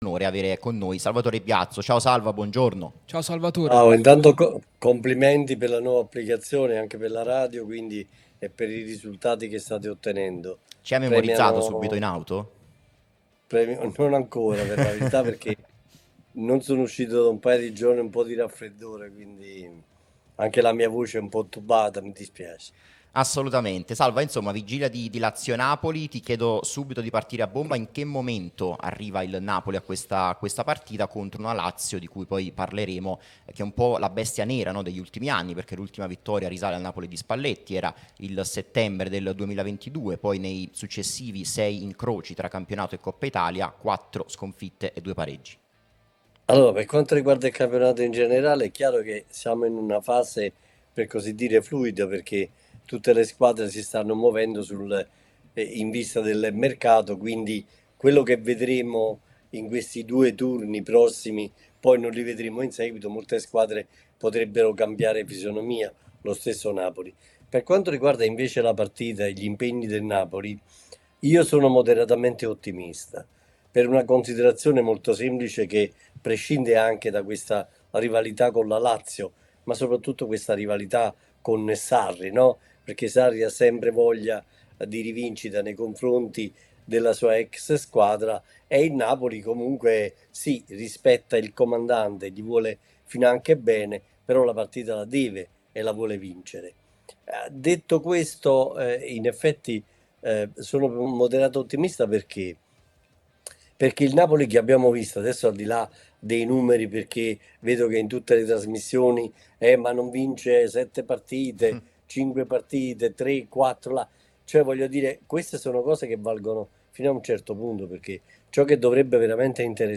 è intervenuto nel corso di 'Sabato Azzurro', trasmissione sulla nostra Radio Tutto Napoli